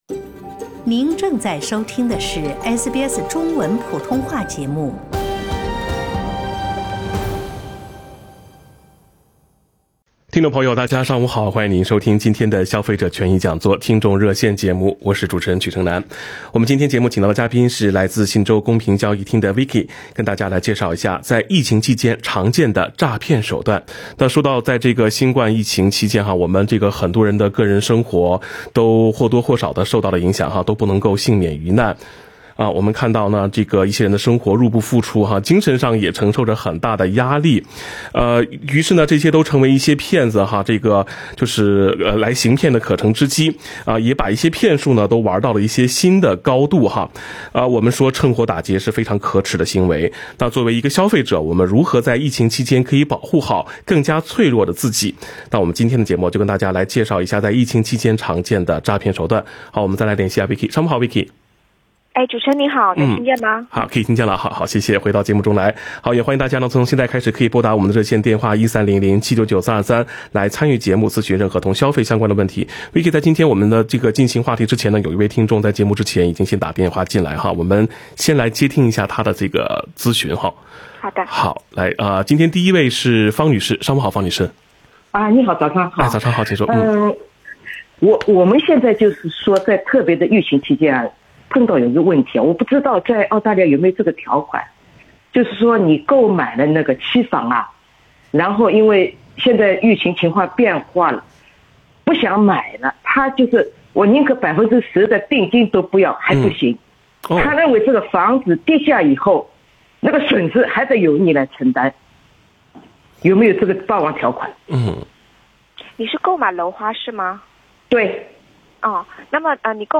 点击文章顶部图片，收听完整热线节目录音。